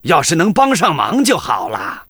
文件 文件历史 文件用途 全域文件用途 Rt_fw_01.ogg （Ogg Vorbis声音文件，长度2.1秒，111 kbps，文件大小：28 KB） 源地址:游戏语音 文件历史 点击某个日期/时间查看对应时刻的文件。 日期/时间 缩略图 大小 用户 备注 当前 2018年5月20日 (日) 14:52 2.1秒 （28 KB） 地下城与勇士  （ 留言 | 贡献 ） 分类:诺顿·马西莫格 分类:地下城与勇士 源地址:游戏语音 您不可以覆盖此文件。